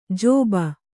♪ jōba